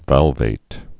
(vălvāt)